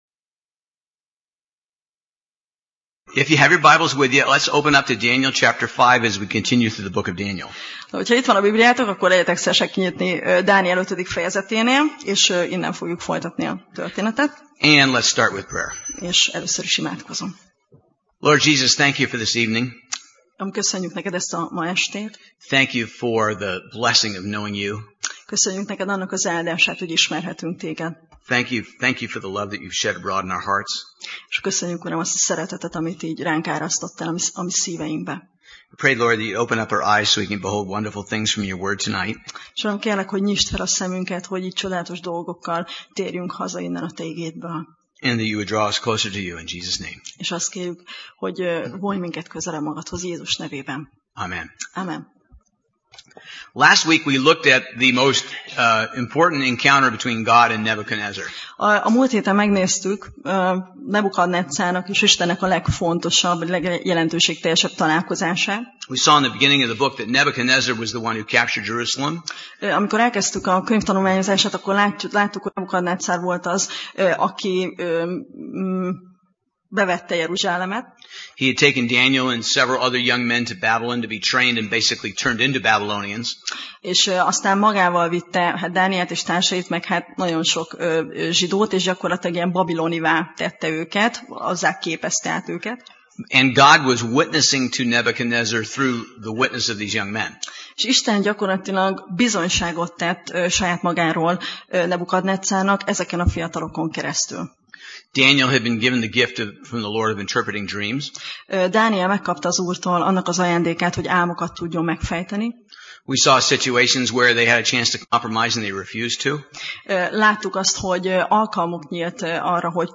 Passage: Dániel (Daniel) 5 Alkalom: Szerda Este